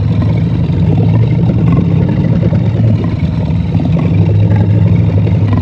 File:Sfx creature squidshark chase loop 01.ogg - Subnautica Wiki
Sfx_creature_squidshark_chase_loop_01.ogg